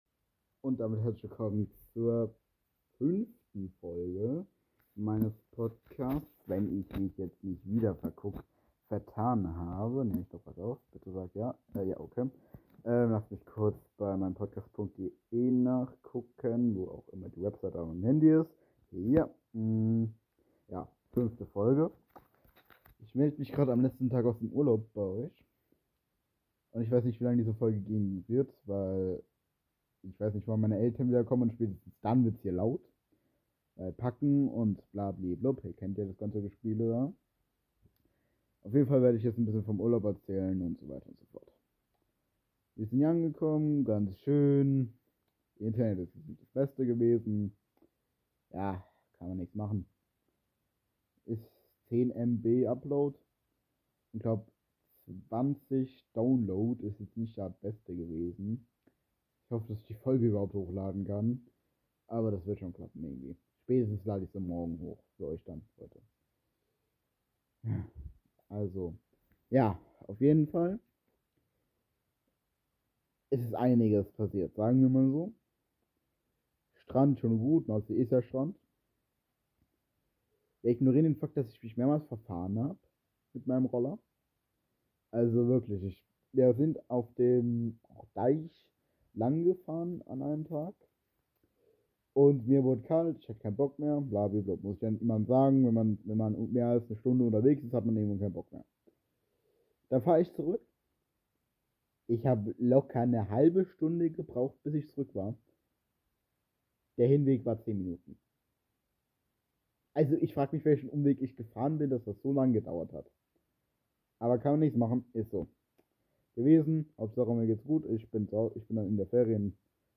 Folge 5 Keine ahnung was ich schreiben soll Und sorry ich musste mein Handy benutzen um die folge aufzunehmen